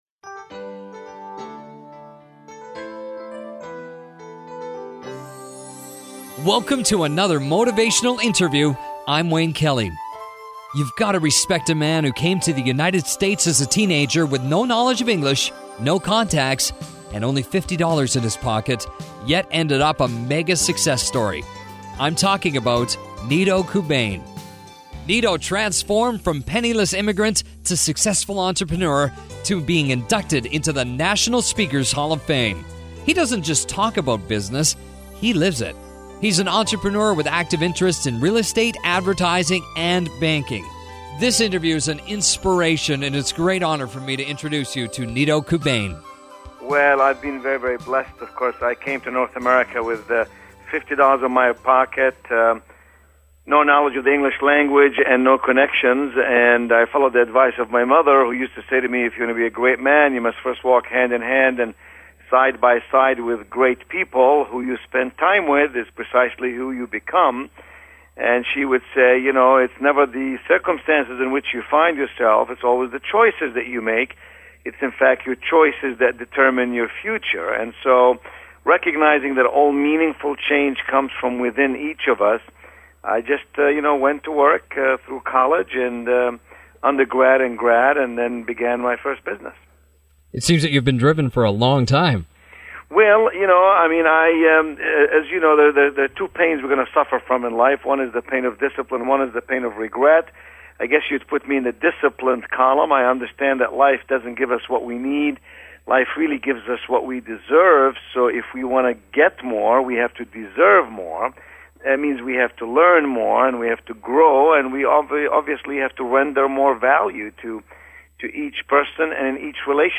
Radio interviews get listeners excited about life.